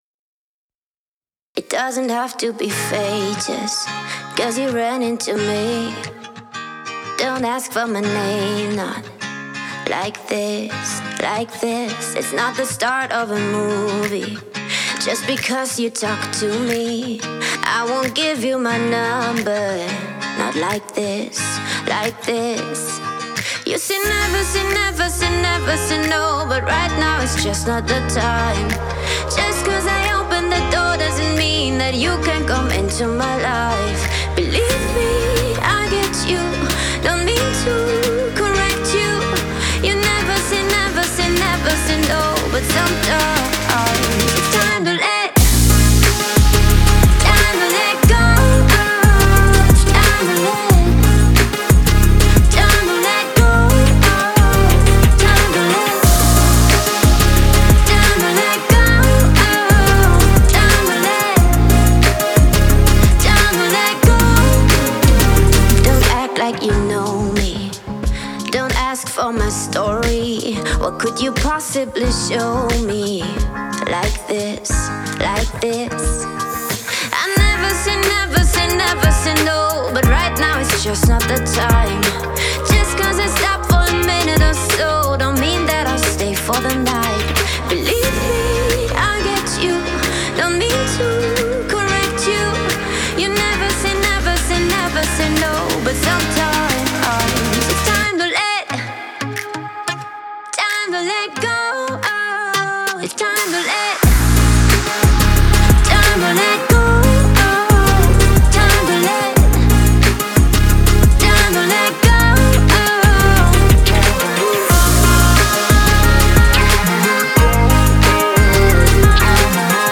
это энергичная трек в жанре электронной музыки